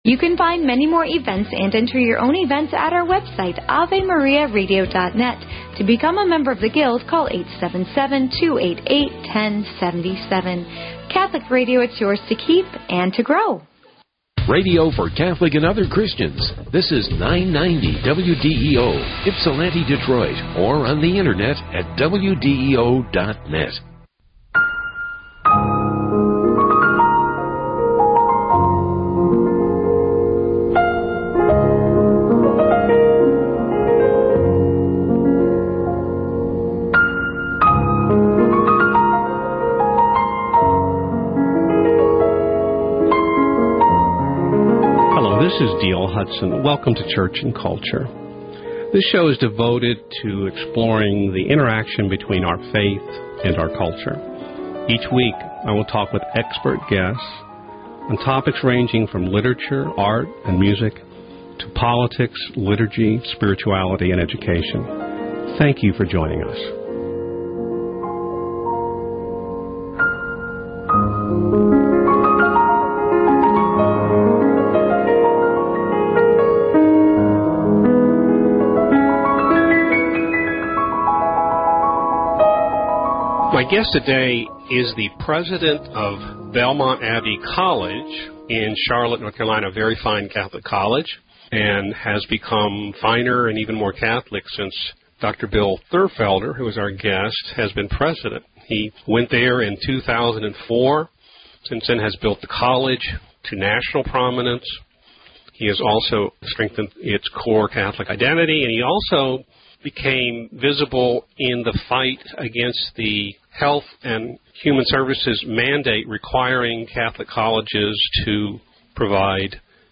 An ongoing archive of my publications and media interviews.